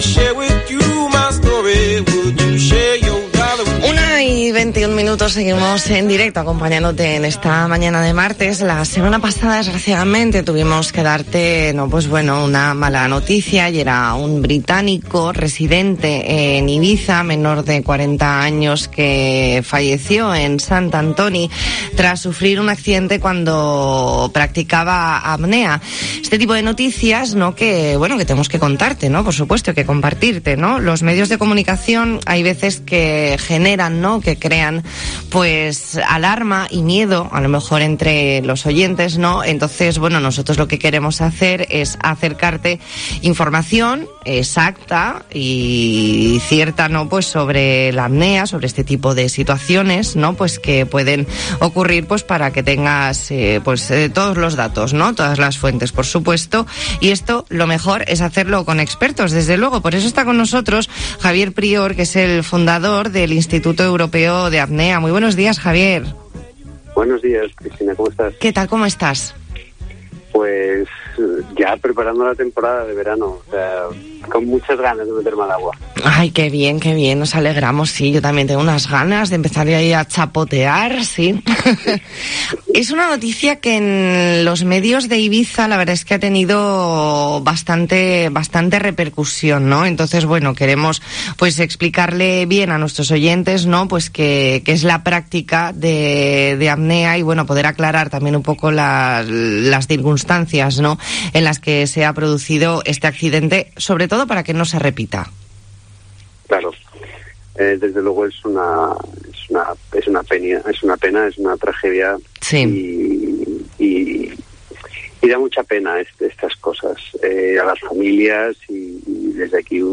Entrevista en La Mañana en COPE Más Mallorca, martes 4 de mayo de 2021.